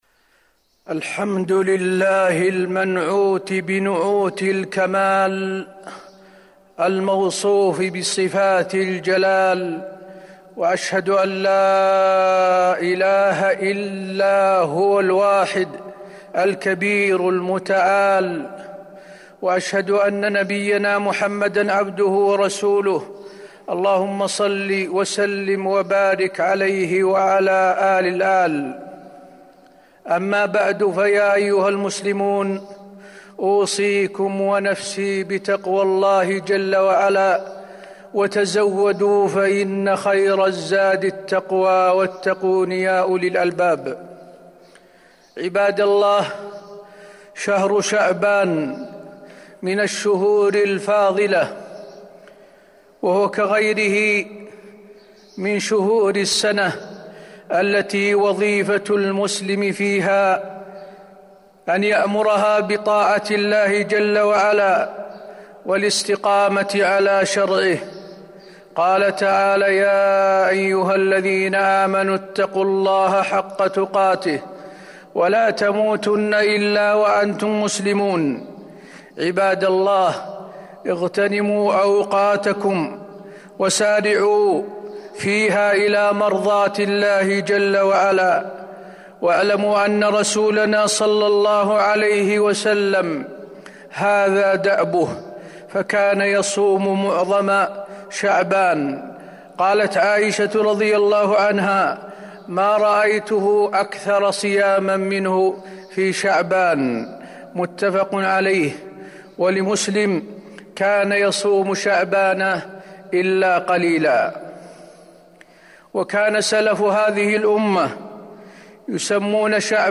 تاريخ النشر ٦ شعبان ١٤٤٢ هـ المكان: المسجد النبوي الشيخ: فضيلة الشيخ د. حسين بن عبدالعزيز آل الشيخ فضيلة الشيخ د. حسين بن عبدالعزيز آل الشيخ فضل شهر شعبان